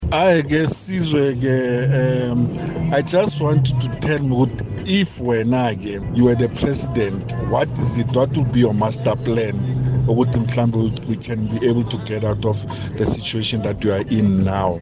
However, as was evident by listener’s views on Kaya Drive, not many share the President’s sentiments.